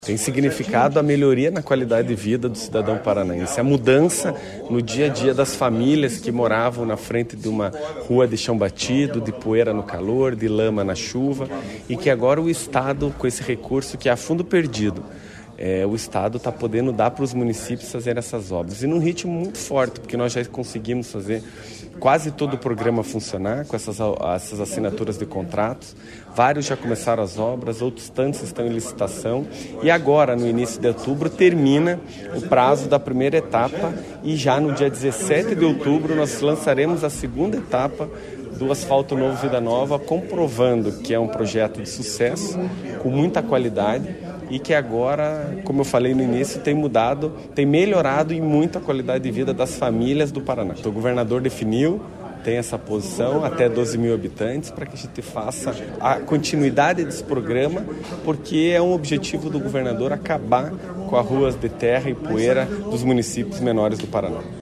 Sonora do secretário das Cidades, Eduardo Pimentel, sobre a liberação de mais R$ 43,1 milhões para asfalto e iluminação em 10 municípios | Governo do Estado do Paraná